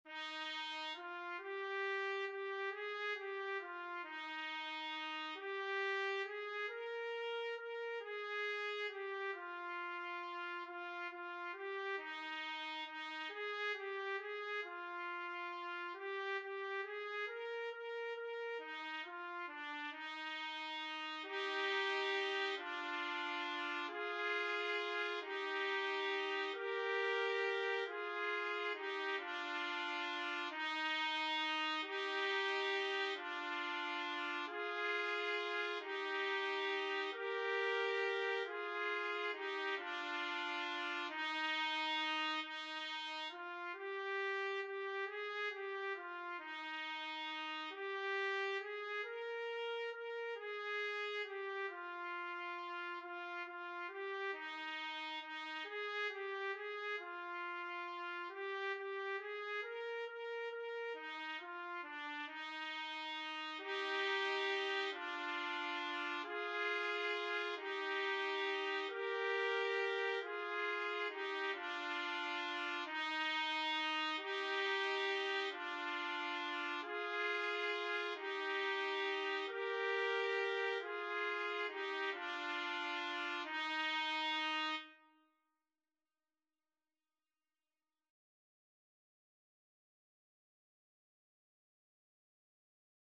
Title: Virgem Santa, Deus te escolheu Composer: Anonymous (Traditional) Lyricist: Number of voices: 1v Voicing: Unison Genre: Sacred, Sacred song
Language: Portuguese Instruments: Organ